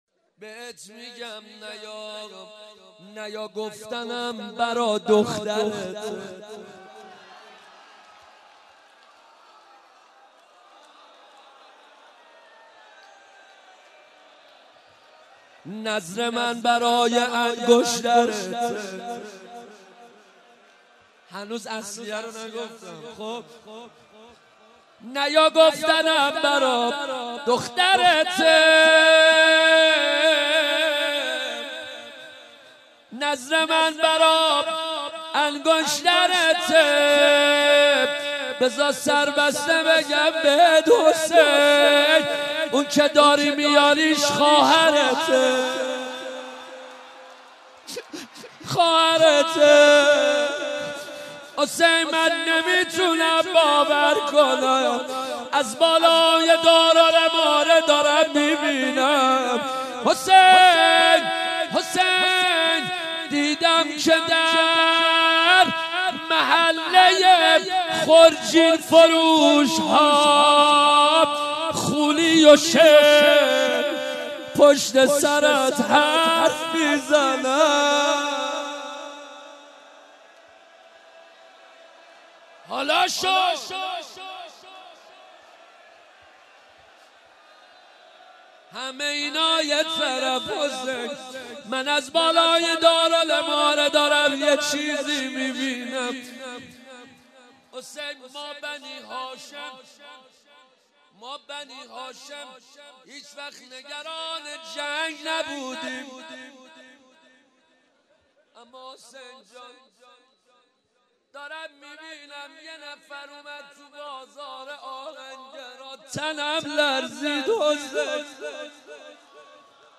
روضه شب اول